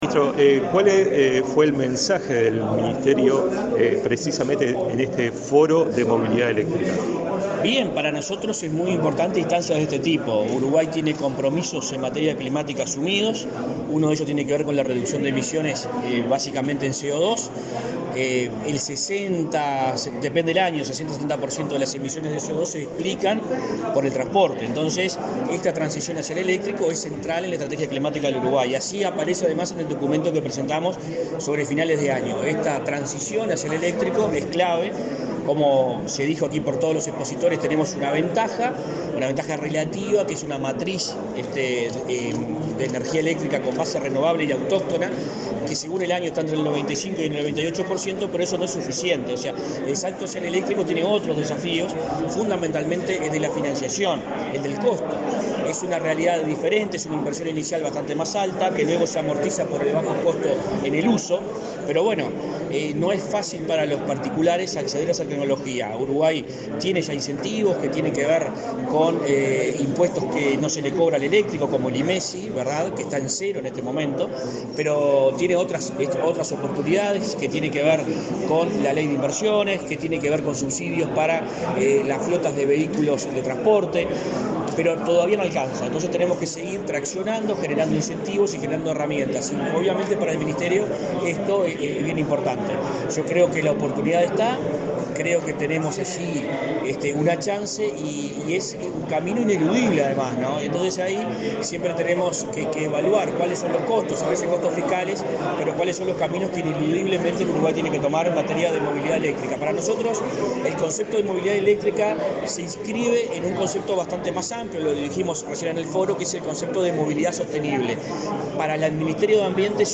Declaraciones a la prensa del ministro de Ambiente, Adrián Peña
El ministro de Ambiente, Adrián Peña, participó este viernes 25 en Punta del Este, Maldonado, en el Segundo Foro Internacional de Movilidad Eléctrica.
Luego dialogó con la prensa.